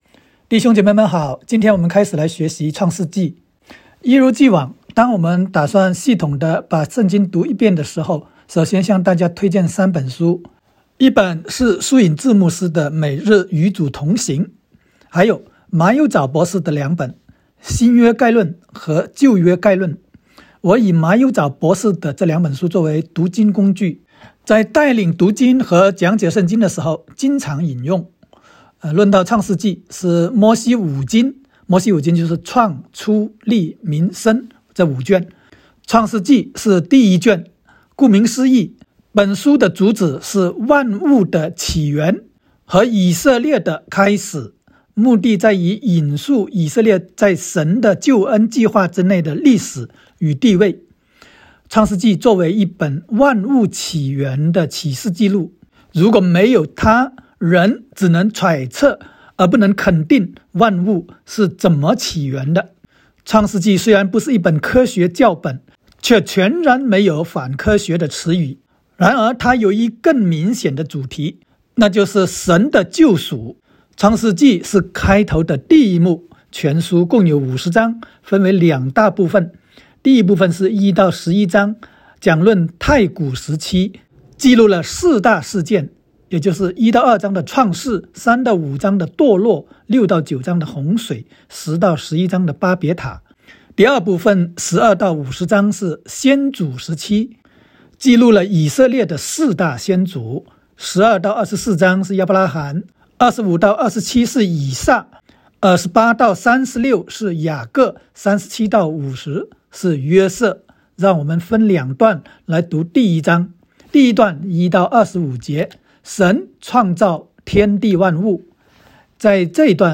创01（讲解-国）.m4a